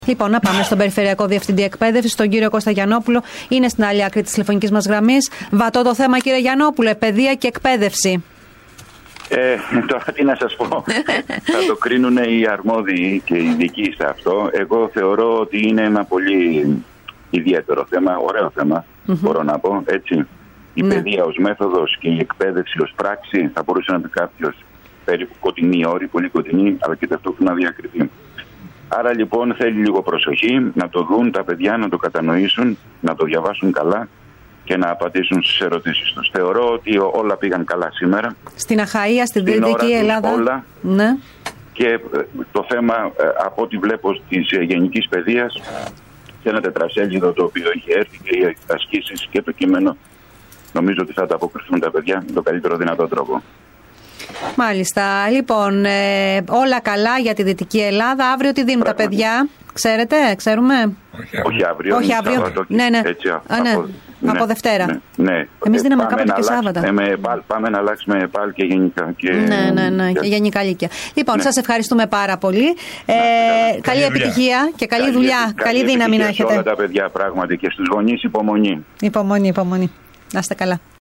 Μιλώντας  στην ΕΡΤ ΠΑΤΡΑΣ ο Περιφερειακός Διευθυντής Εκπαίδευσης Κώστας Γιαννόπουλος,  τόνισε  πως  τα  θέματα δόθηκαν έγκαιρα  και όλα κύλησαν ομαλά.
ΣΥΝΕΝΤΕΥΞΗ-Π.-Δ.ΜΕΣΗΣ-ΕΚΠΑΙΔΕΥΣΗΣ.-Κ.-ΓΙΑΝΝΟΠΟΥΛΟΣ..mp3